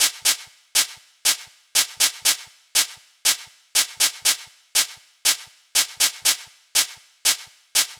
Ew Hats.wav